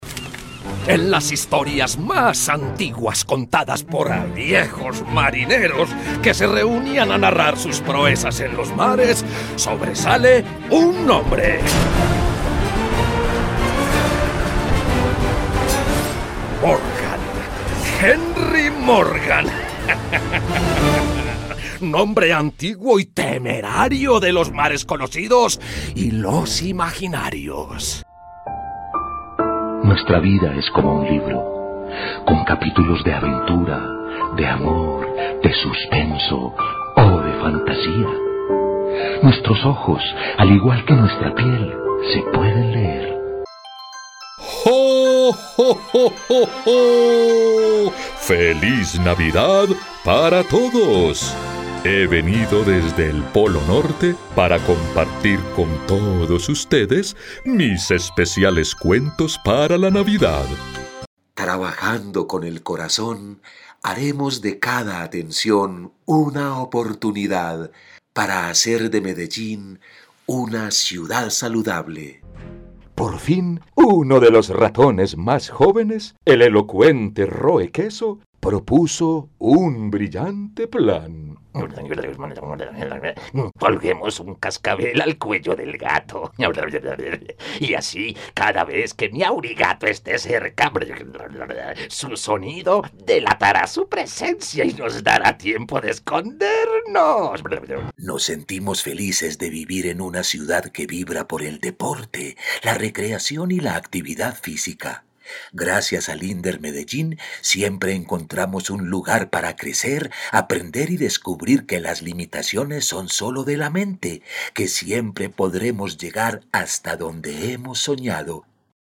Masculino